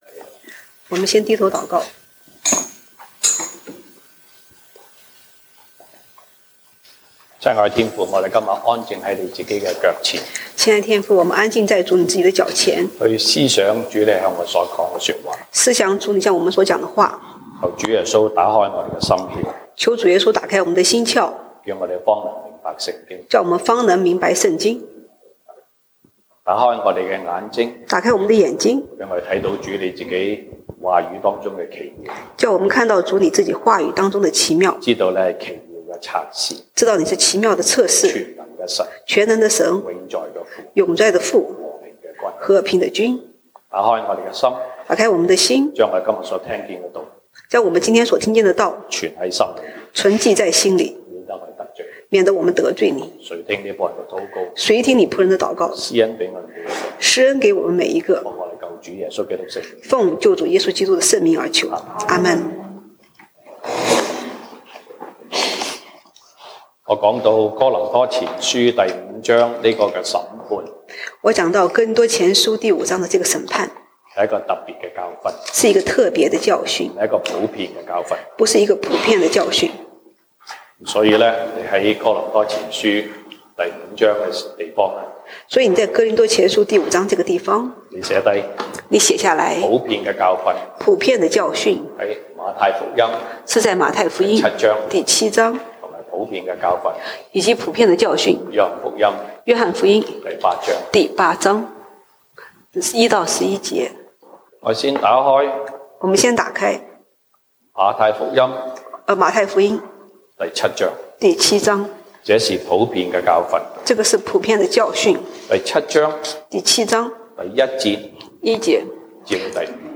西堂證道(粵語/國語) Sunday Service Chinese: 這就是神如何拯救罪人
Service Type: 西堂證道(粵語/國語) Sunday Service Chinese Topics: 這就是神如何拯救罪人